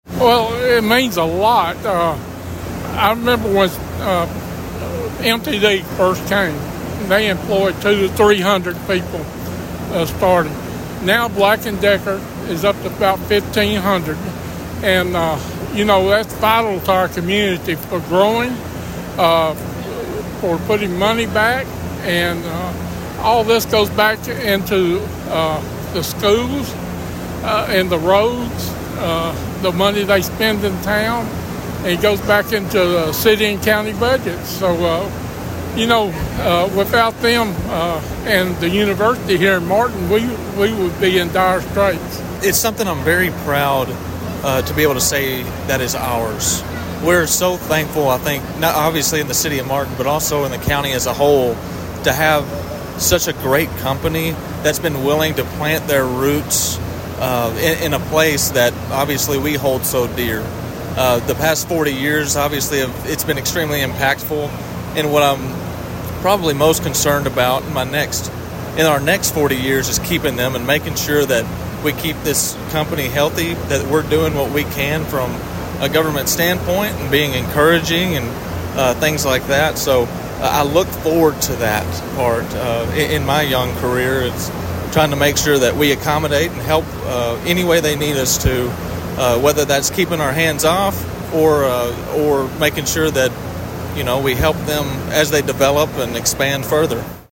Approximately 700 people came to tour the Stanley Black and Decker open house Saturday in recognition of the plant’s 40 years in Martin.
Martin Mayor Randy Brundige and Weakley County Mayor Dale Hutcherson made the tour and shared their thoughts about what Stanley Black and Decker means to the Ken Tenn area.